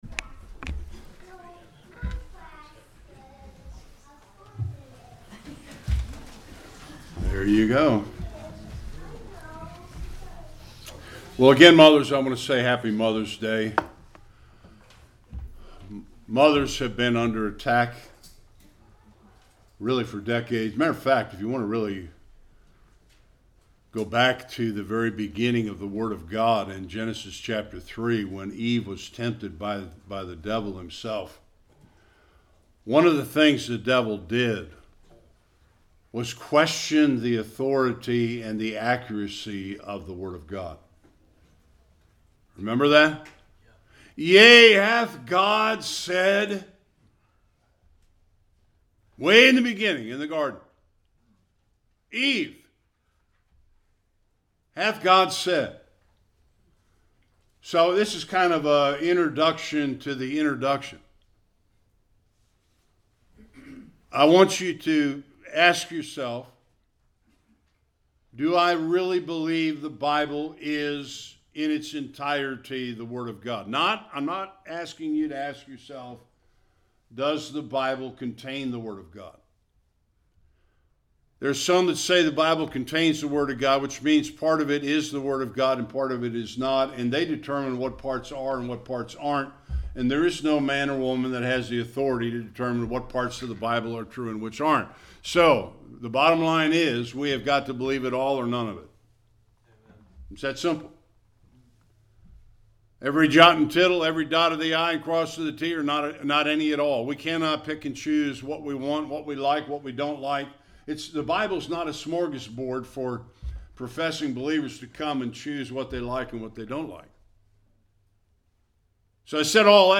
Various Passages Service Type: Sunday Worship There is no substitute for a Godly mother.